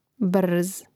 bȓz brz